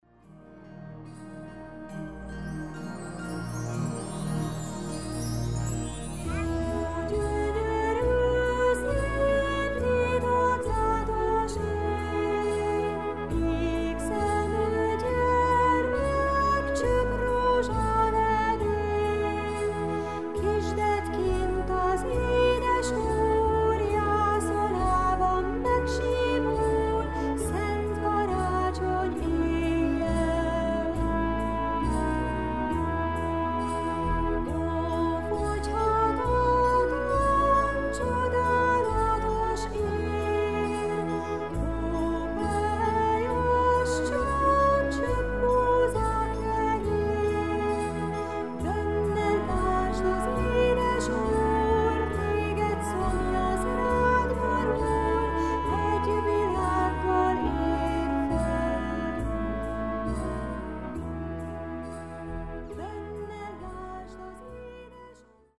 ének
karácsonyi dalok babáknak